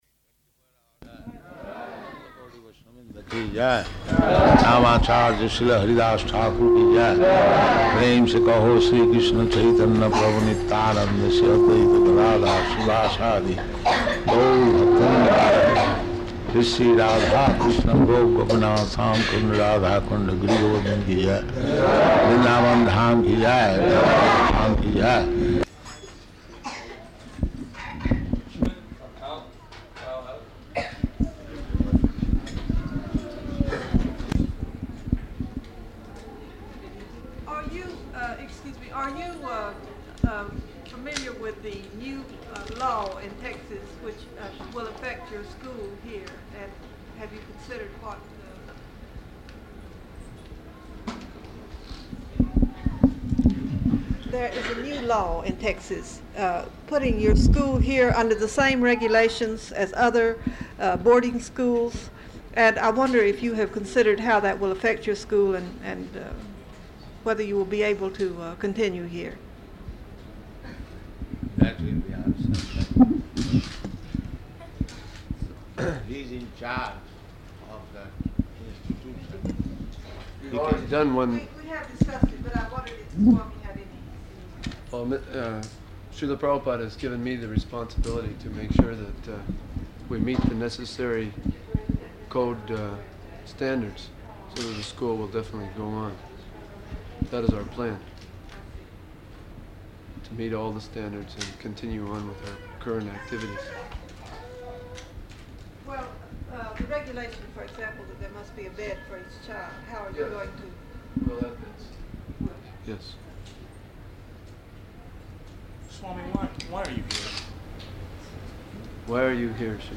Press Conference at Airport
Press Conference at Airport --:-- --:-- Type: Conversation Dated: July 28th 1975 Location: Dallas Audio file: 750728PC.DAL.mp3 Prabhupāda: [leads prema-dhvāṇī prayers] [break] Woman reporter: Are you...